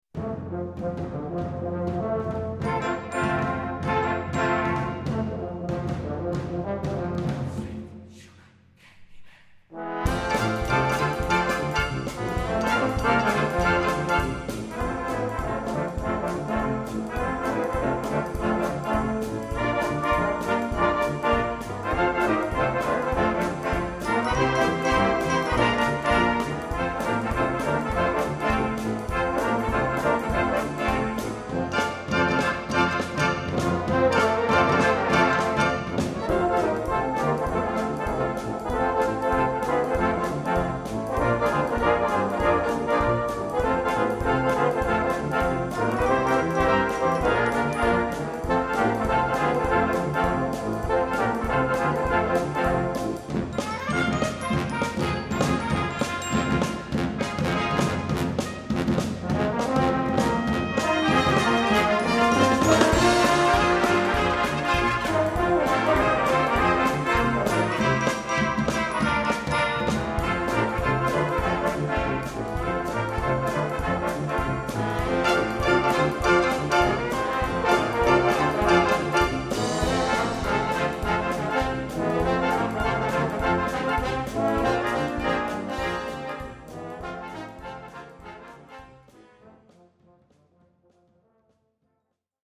Dieser dynamische Song
jazzigen Big-Bang-Stils
Noten für Blasorchester, oder Brass Band.